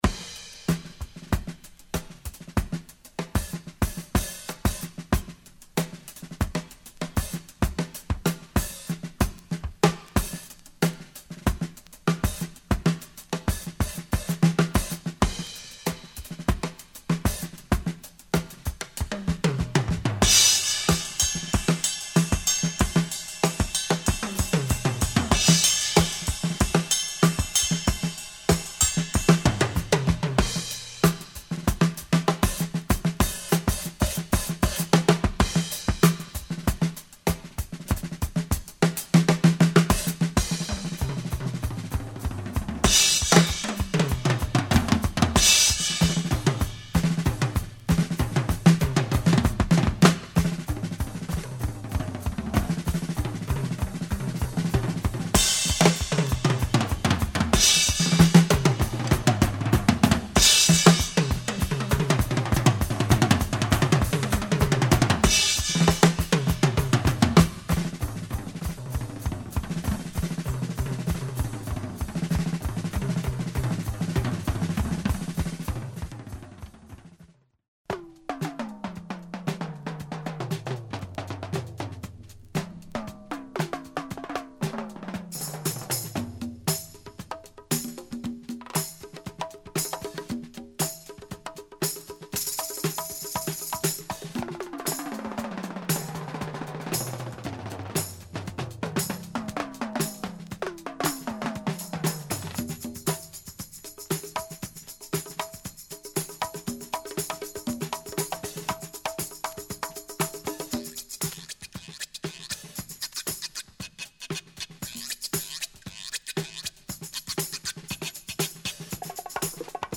Listen to these breaks and go make some beats !